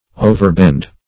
Meaning of overbend. overbend synonyms, pronunciation, spelling and more from Free Dictionary.